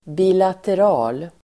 Uttal: [bi:later'a:l]